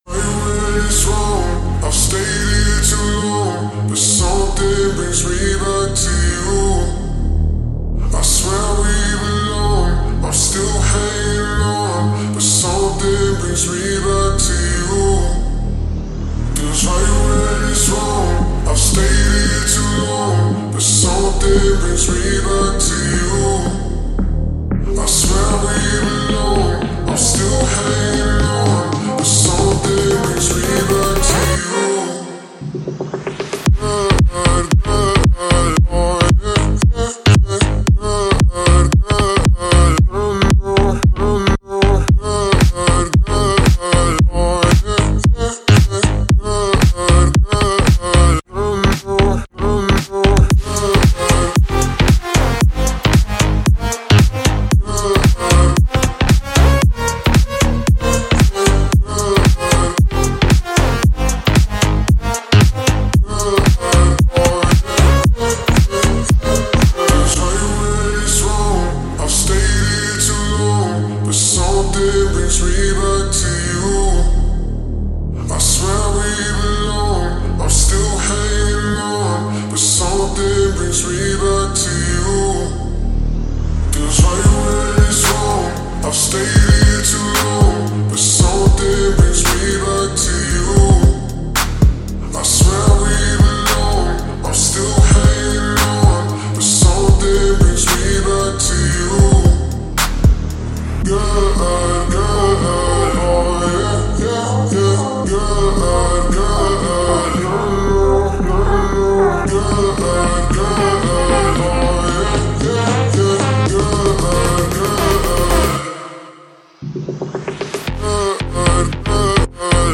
это энергичный трек в жанре хип-хоп
выделяется харизмой и динамичным флоу